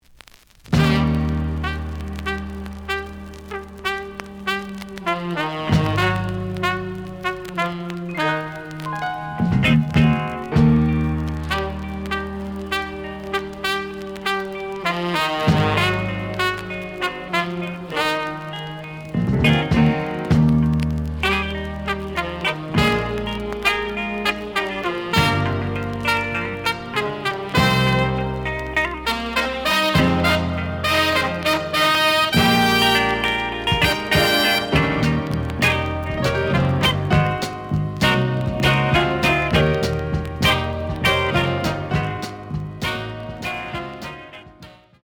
The audio sample is recorded from the actual item.
●Genre: Funk, 70's Funk